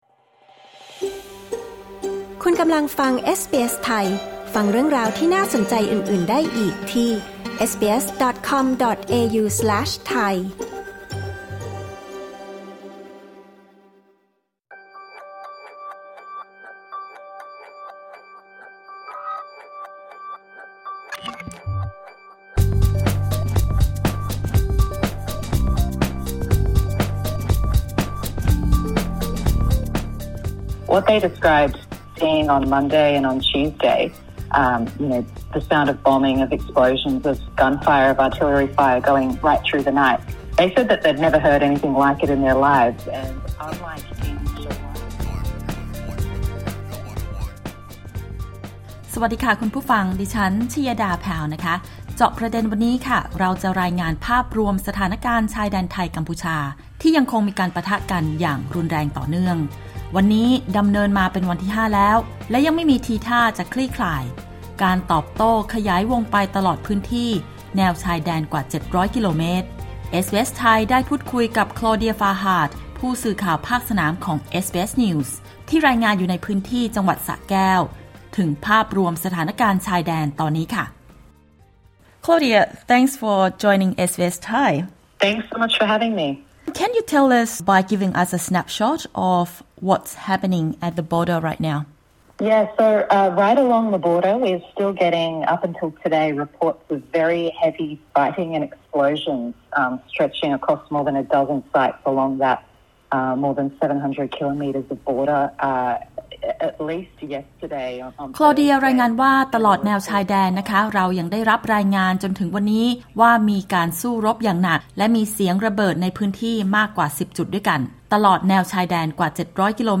รายงานสดจากชายแดนไทย–กัมพูชา: การสู้รบรุนแรงต่อเนื่อง ปชช. อพยพแล้วเกิน 5 แสนคน